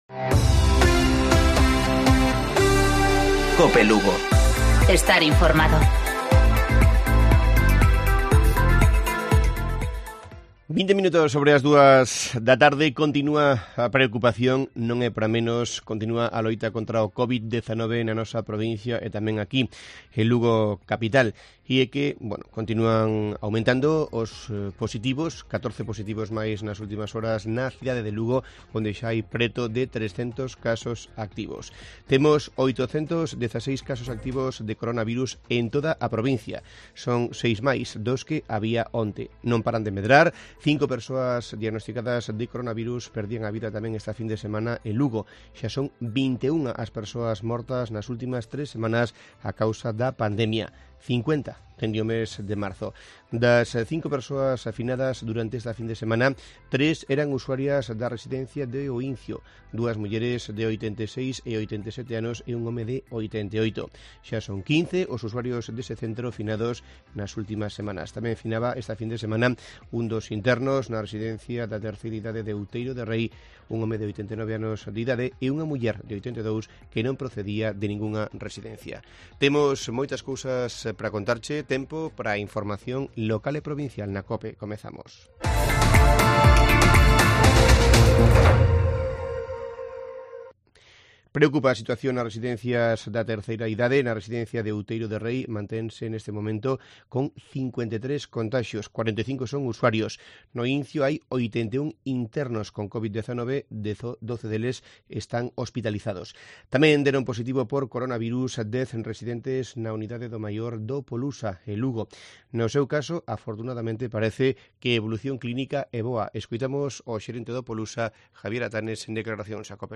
Informativo Mediodía Cope Lugo. 07 de septiembre. 14:20 horas